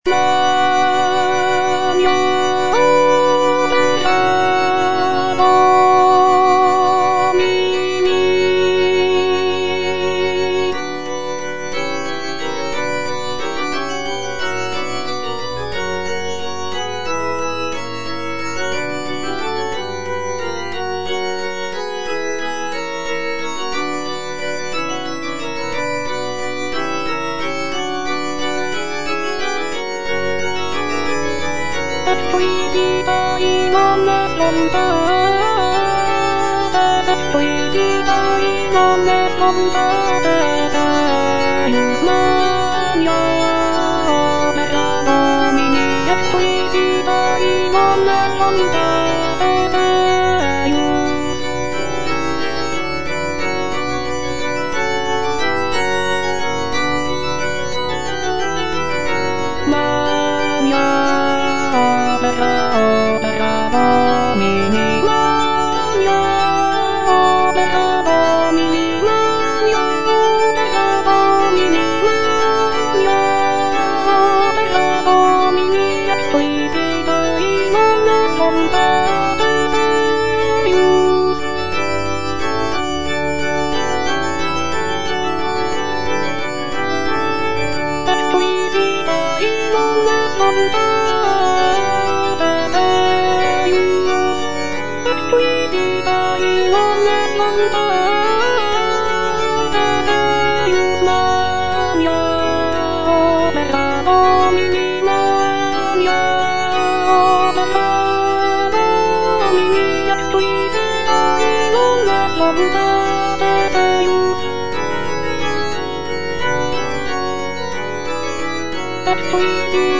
M.R. DE LALANDE - CONFITEBOR TIBI DOMINE Magna opera Domini - Alto (Voice with metronome) Ads stop: auto-stop
It is a setting of the Latin text from Psalm 111, expressing gratitude and praise to the Lord. Lalande's composition features intricate polyphony, lush harmonies, and expressive melodies, reflecting the Baroque style of the period.